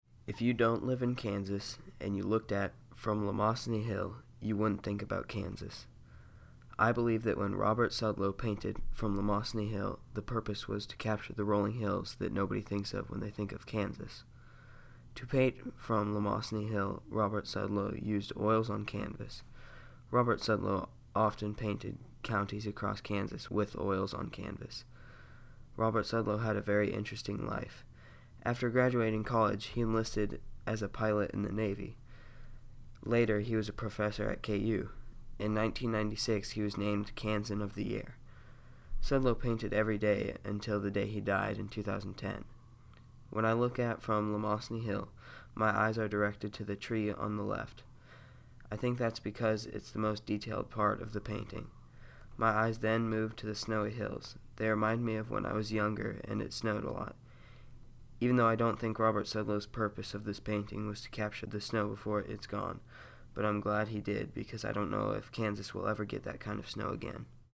Audio Tour – Bulldog Art Tour